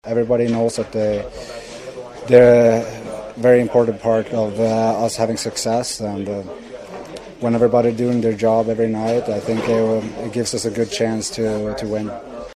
Rickard Rakell says every player on the Pens’ roster knows their role.